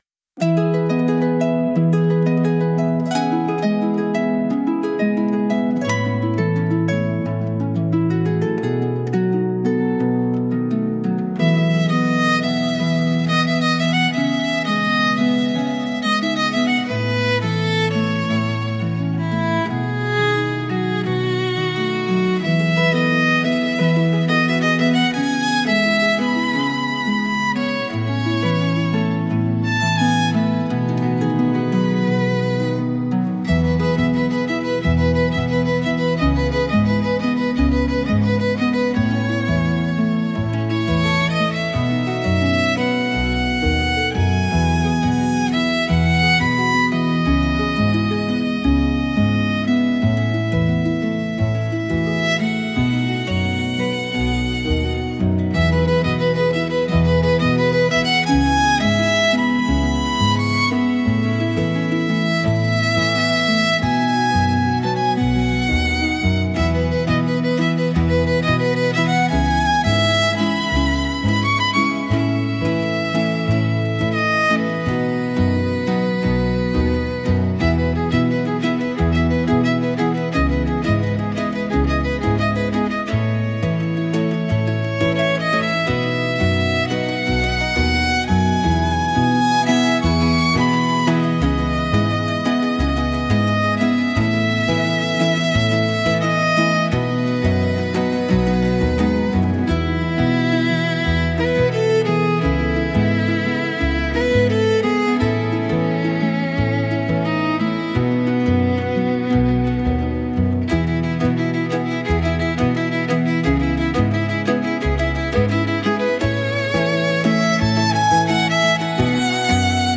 música, arranjo e voz: IA) (Instrumental)